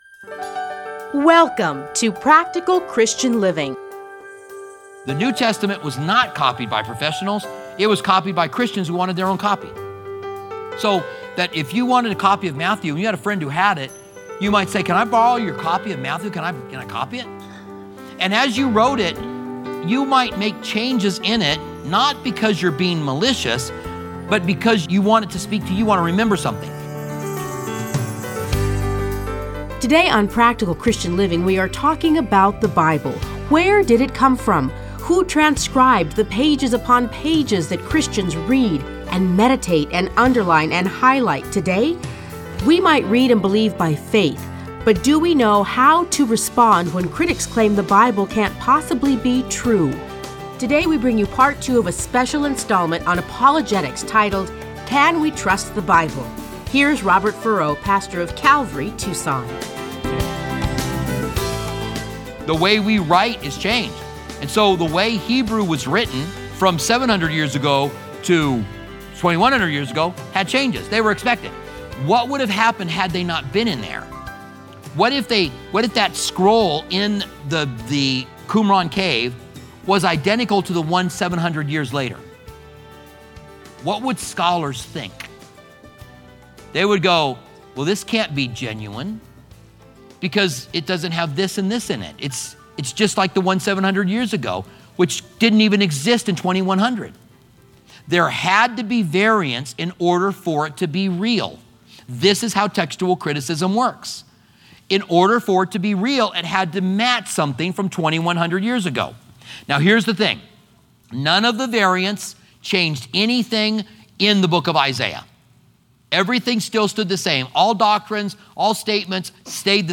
Listen to a teaching from Various Scriptures.